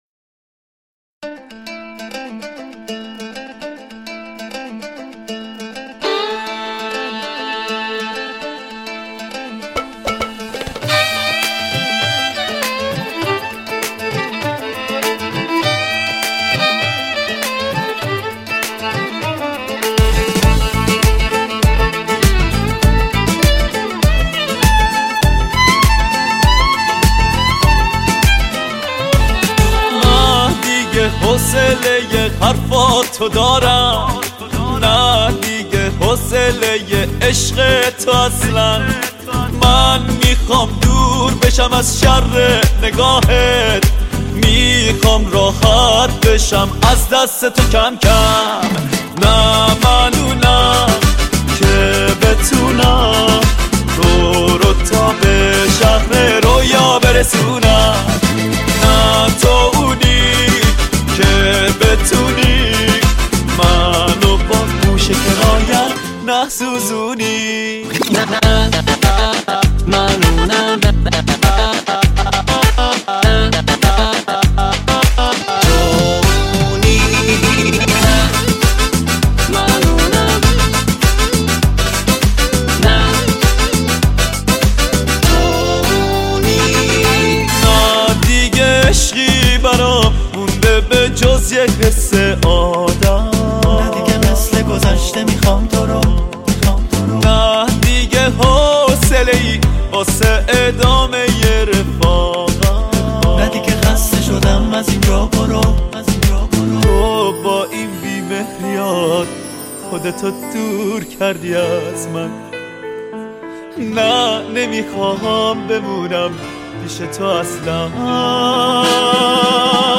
آهنگ شاد
آهنگ فارســی